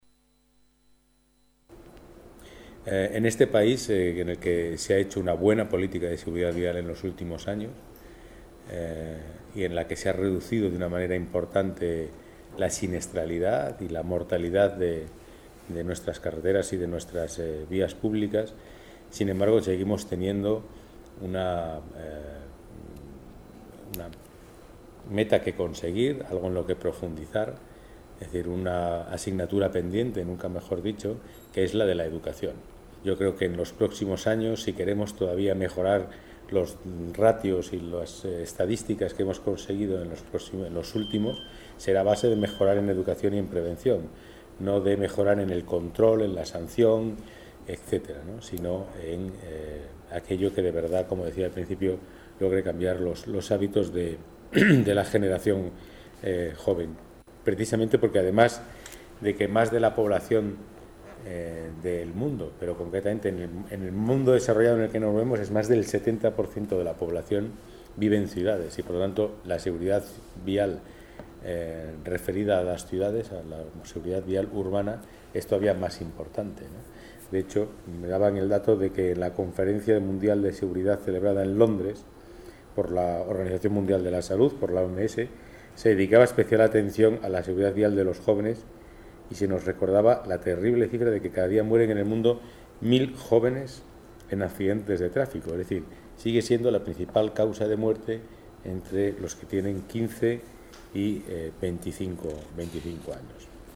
Nueva ventana:Declaraciones delegado Seguridad, Pedro Calvo: convenio seguridad vial y jóvenes